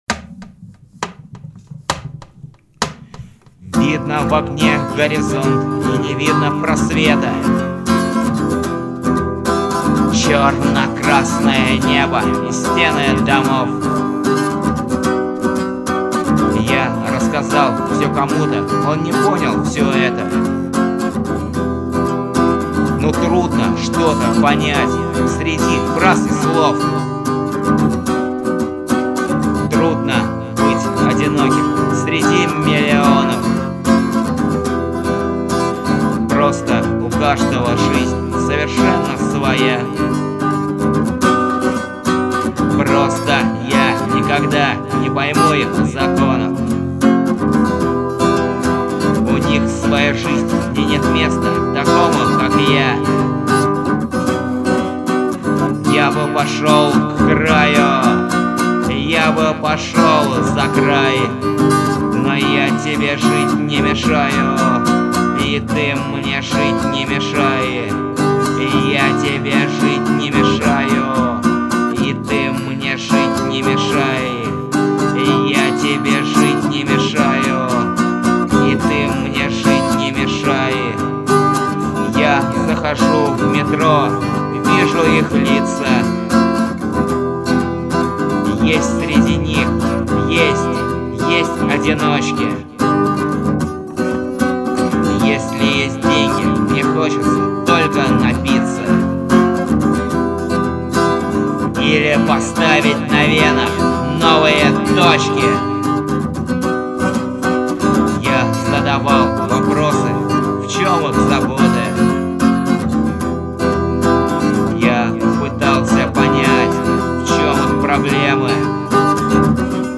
вокал
гитара
Коптевская звукозаписывающая студия, 12 июля 2005 года.